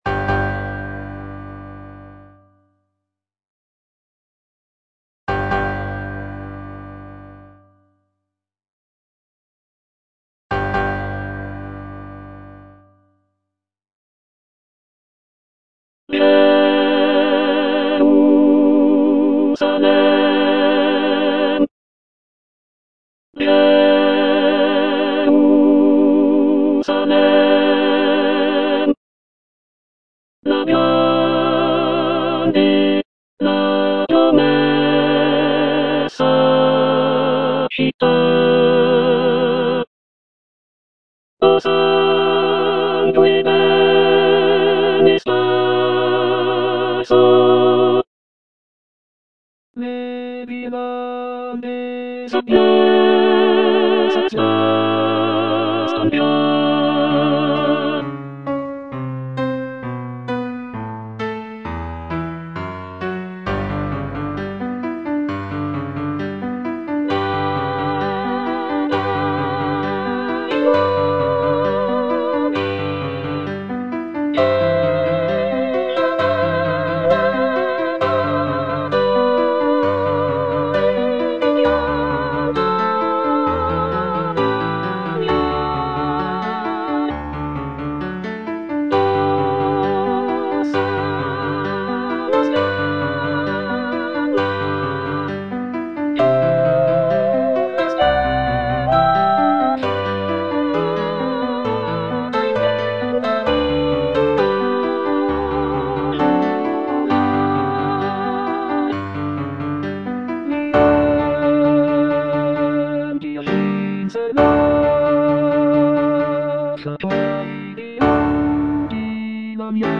The powerful and emotional choral writing, along with the grand orchestration, creates a sense of reverence and anticipation for the epic quest ahead.